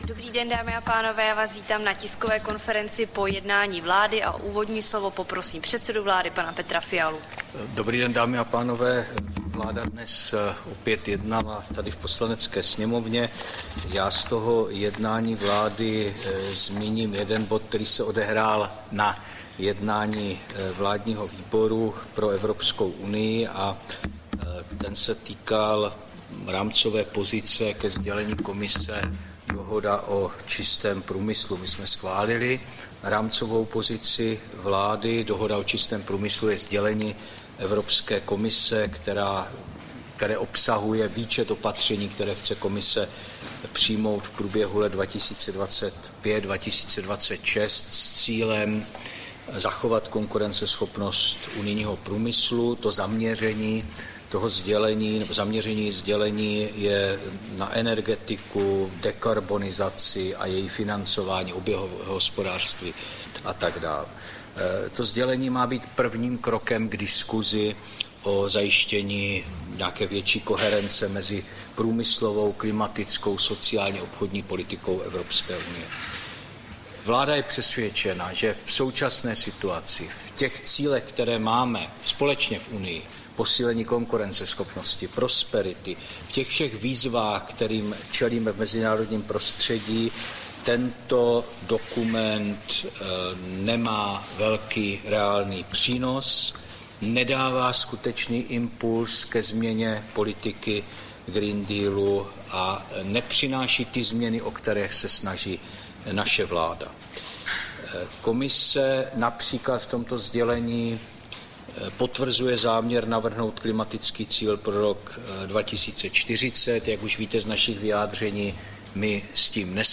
Tisková konference po jednání vlády, 16. dubna 2025